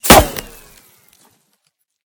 / gamedata / sounds / material / bullet / collide / dirt01gr.ogg 26 KiB (Stored with Git LFS) Raw History
dirt01gr.ogg